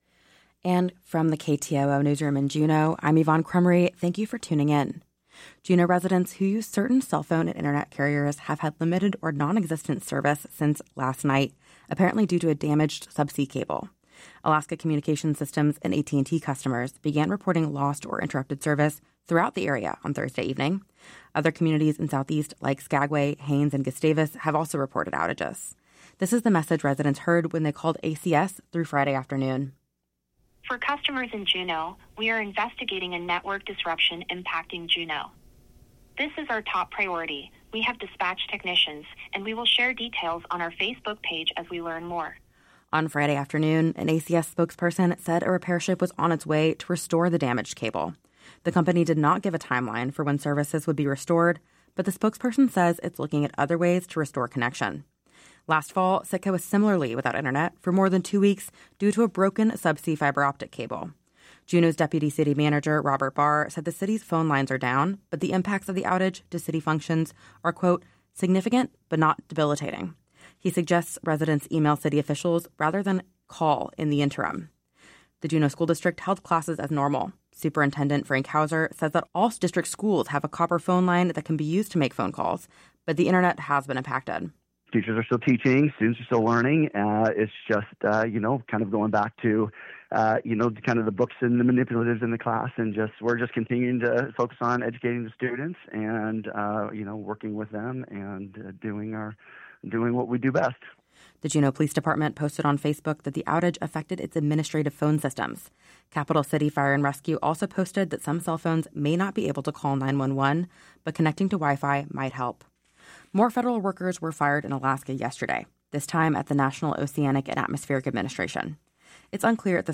Newscast – Friday, Feb. 28, 2025 - Areyoupop